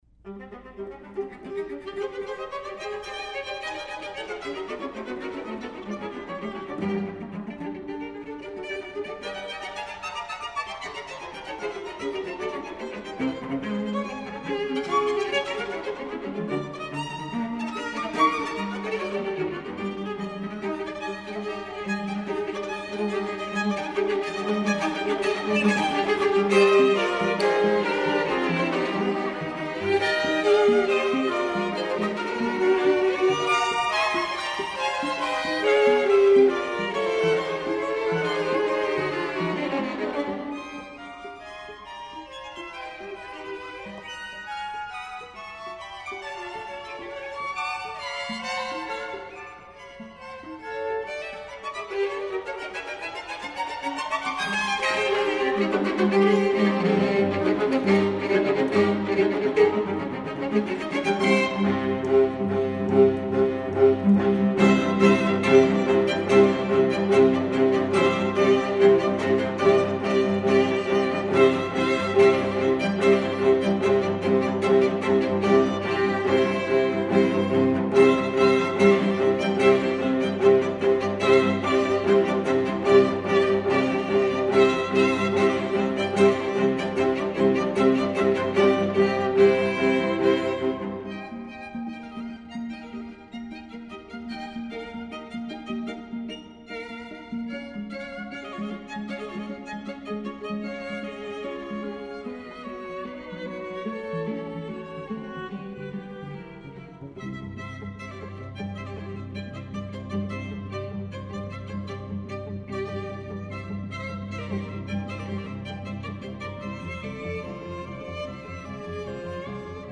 Late Romantic.